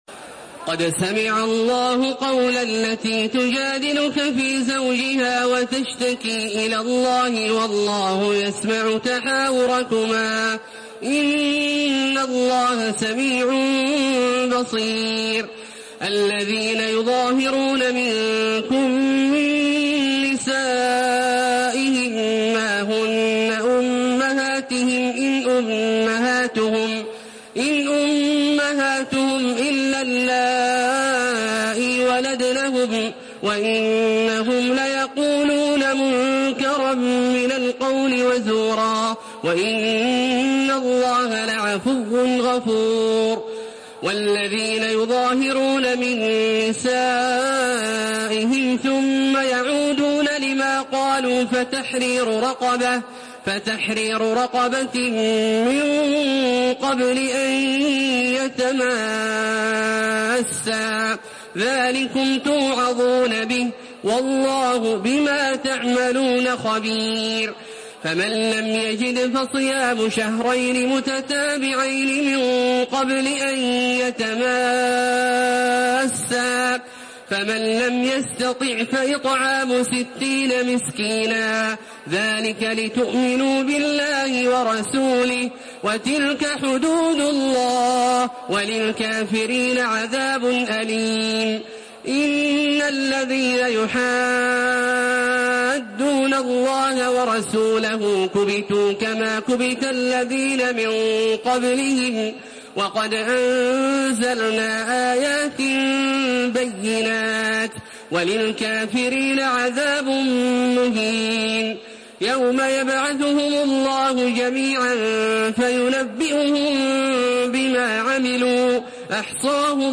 Surah আল-মুজাদালাহ্ MP3 by Makkah Taraweeh 1432 in Hafs An Asim narration.
Murattal Hafs An Asim